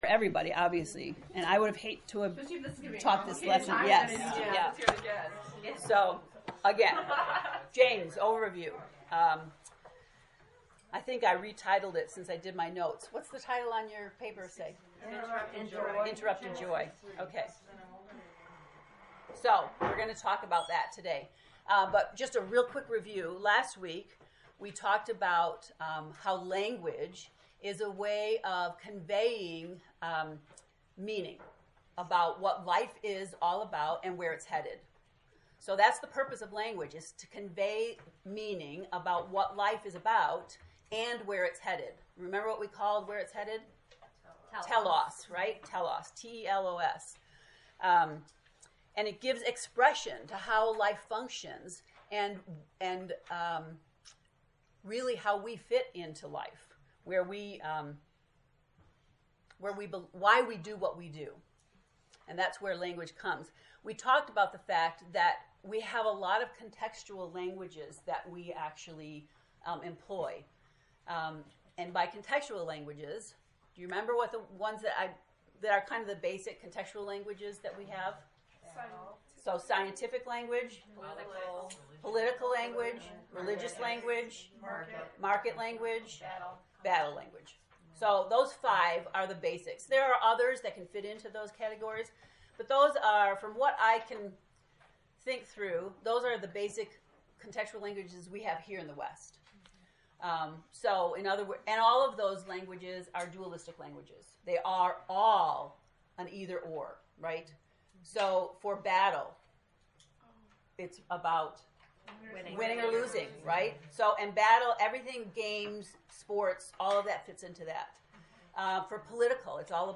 To listen to the lesson 1 lecture, “Joy Interrupted,” click below: